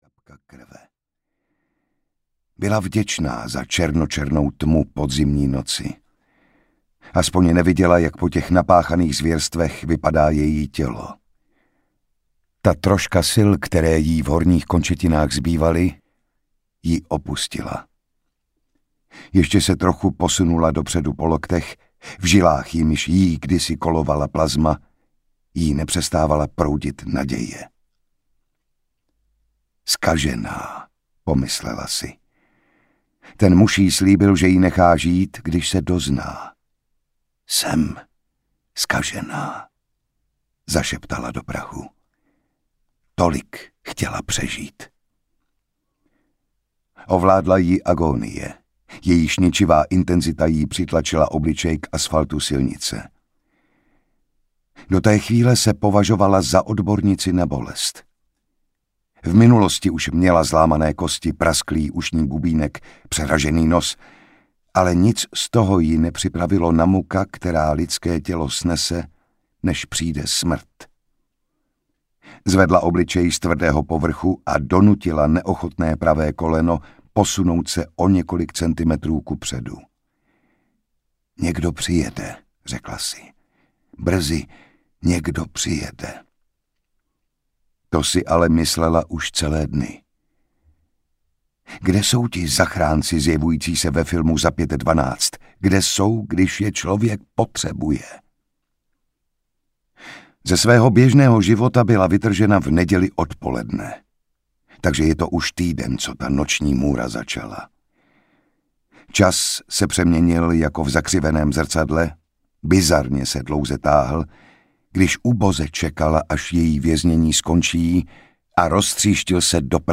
Dokonalé ticho audiokniha
Ukázka z knihy
• InterpretJan Šťastný